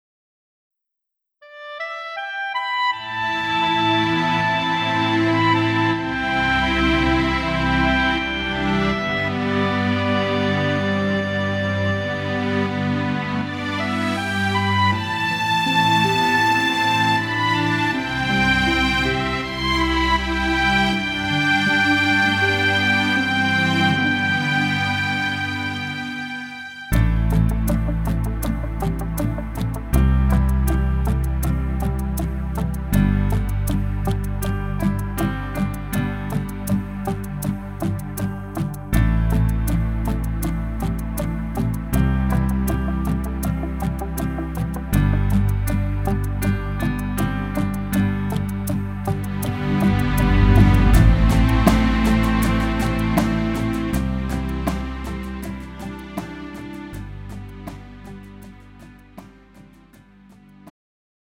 음정 원키 4:53
장르 가요 구분 Pro MR